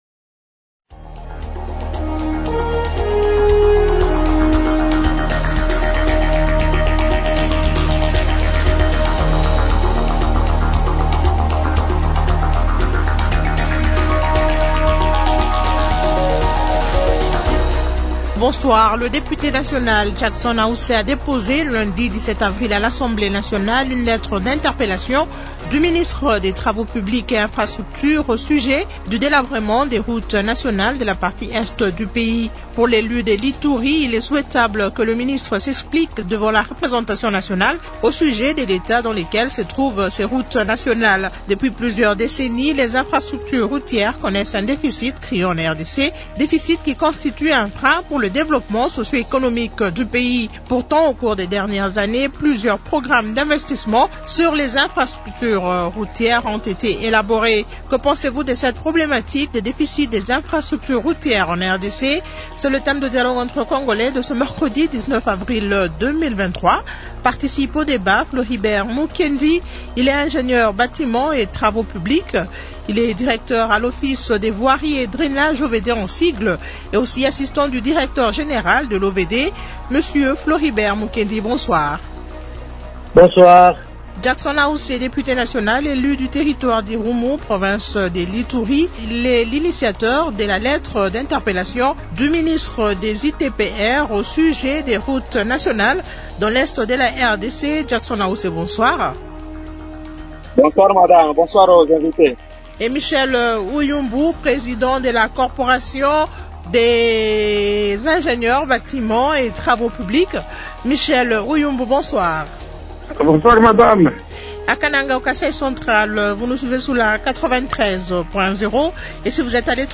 -Que pensez-vous de cette problématique du déficit d’infrastructures routières en RDC ? Invités :